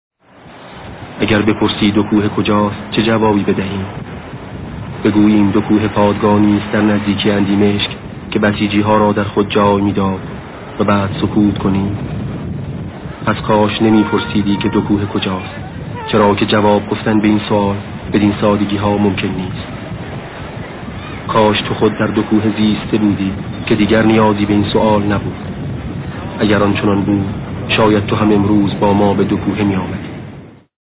فرازی از صدای ماندگار شهید سید مرتضی آوینی دربارۀ دوکوهه، سرزمینی به قداست بهشت